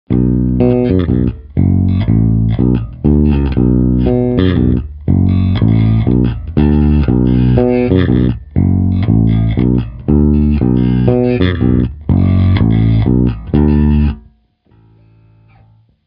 oba
Drzej, mručivej, agresivní.